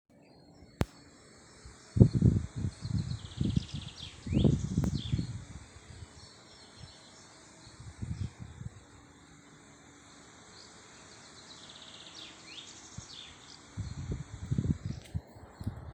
Žubīte, Fringilla coelebs
Administratīvā teritorijaTalsu novads
StatussDzied ligzdošanai piemērotā biotopā (D)